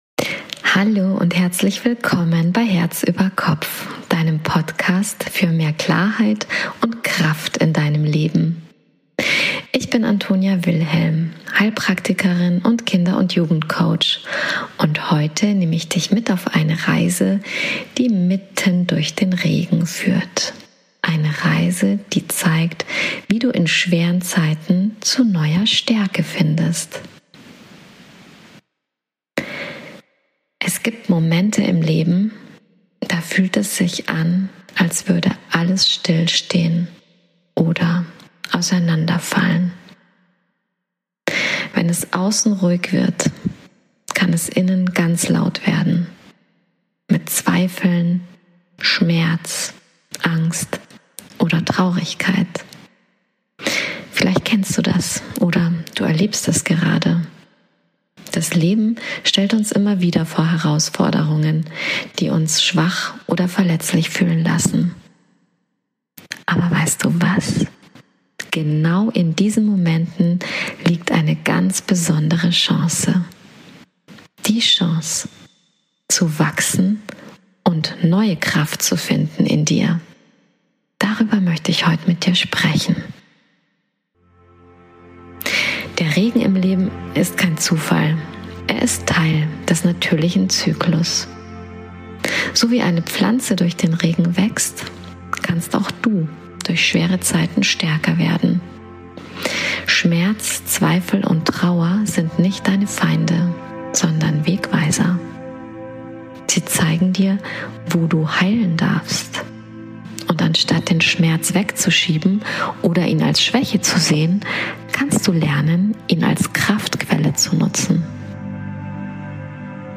Eine geführte Reise erwartet dich: Mitten durch den Regen – hin zu Deiner Lebendigkeit, Deinem Mut, Deinem Licht.